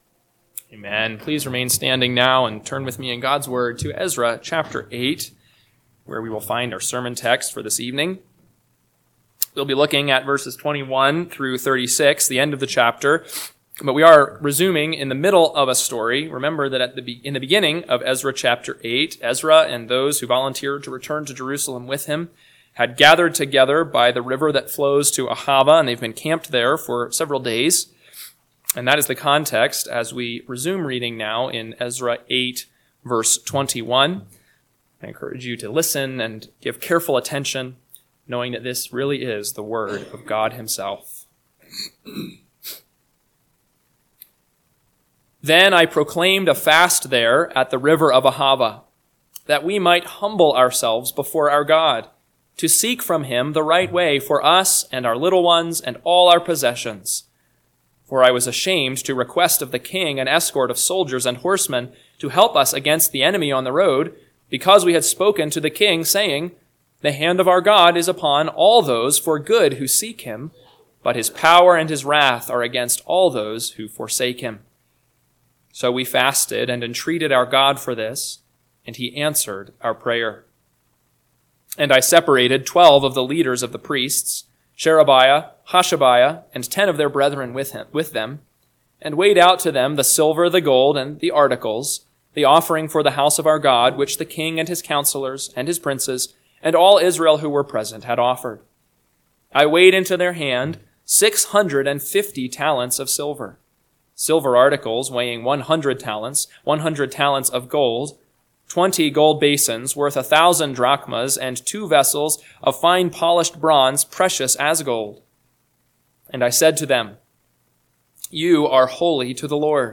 PM Sermon – 5/11/2025 – Ezra 8:21-36 – Northwoods Sermons